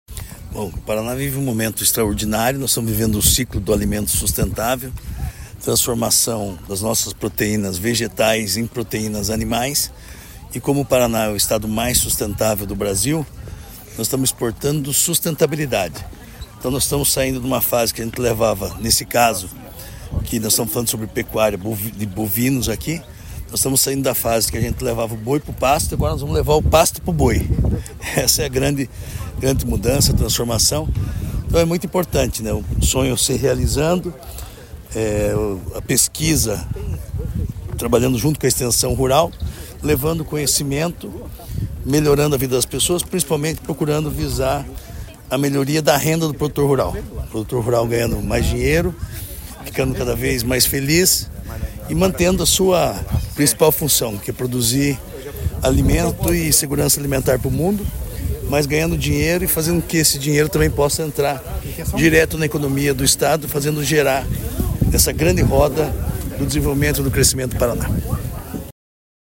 Sonora do secretário da Agricultura e do Abastecimento, Marcio Nunes, sobre a II Mostra Tecnológica da Fazenda-Modelo em Ponta Grossa